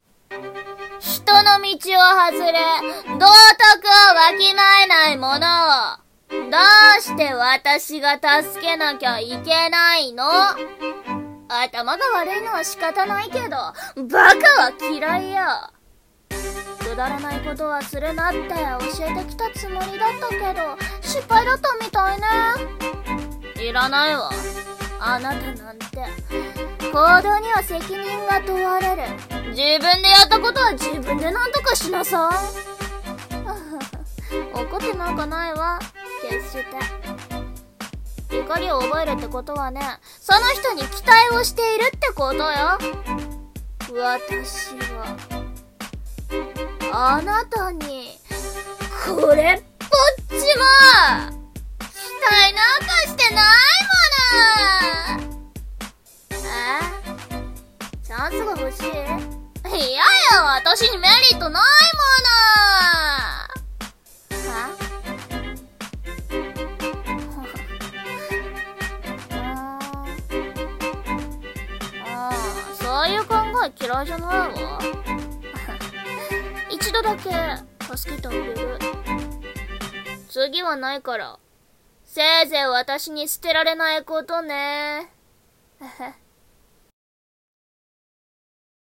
【声劇】期待なんか